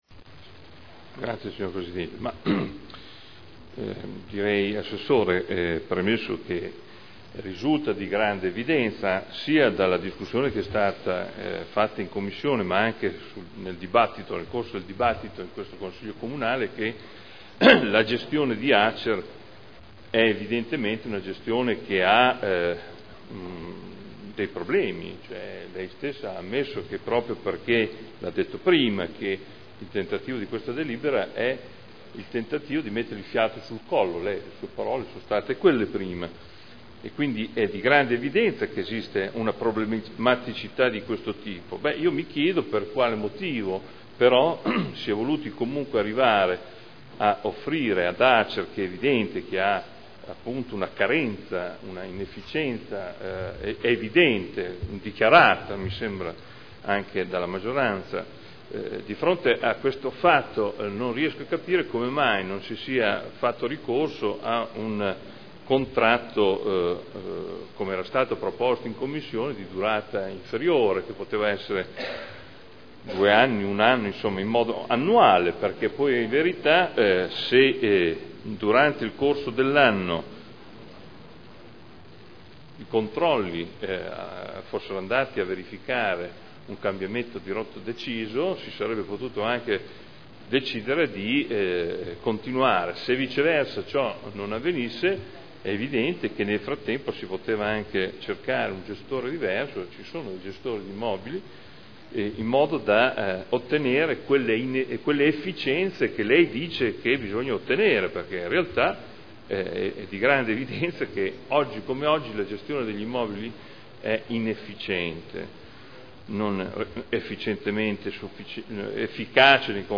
Seduta del 20/12/2010. Dichiarazione di voto su delibera: Affidamento in concessione all’Azienda Casa Emilia Romagna del servizio di gestione del patrimonio di Edilizia Residenziale pubblica di proprietà del Comune – Approvazione Accordo Quadro provinciale e Contratto di Servizio del Comune di Modena (Commissione consiliare del 7 dicembre 2010)